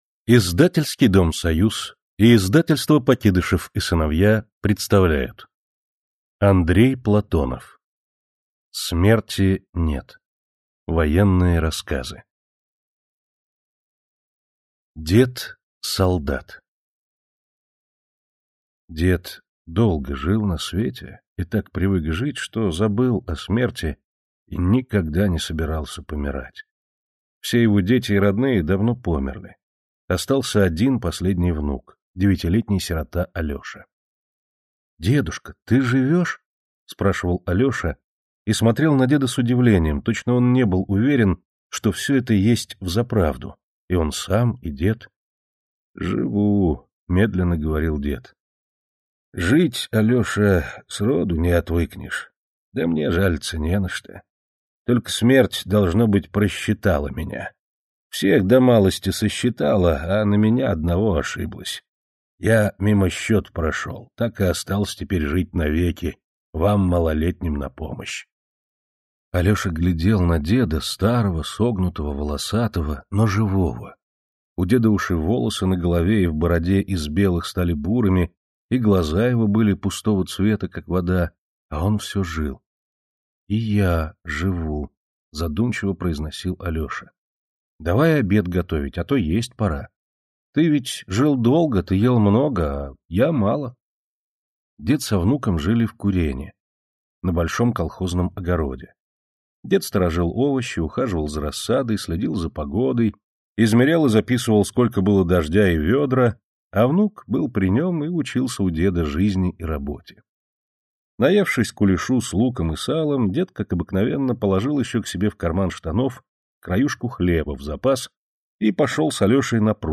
Аудиокнига Дед-солдат и другие рассказы | Библиотека аудиокниг